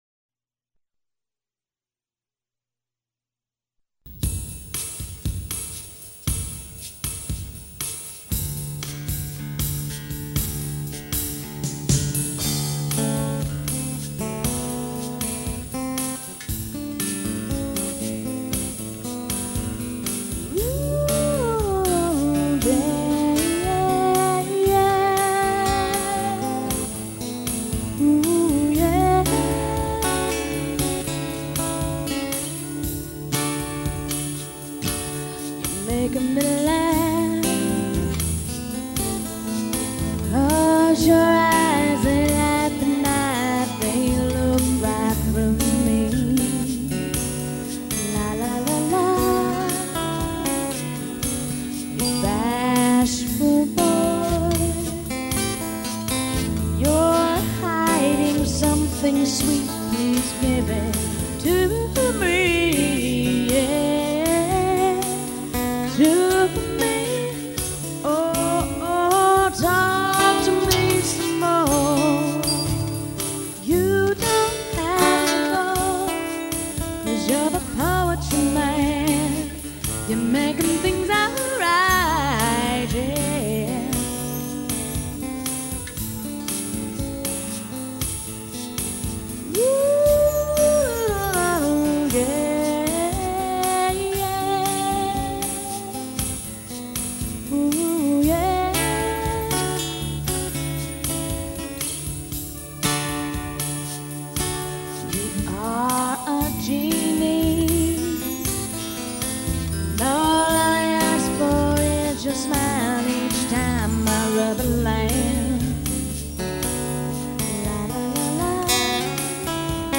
Jammout sessions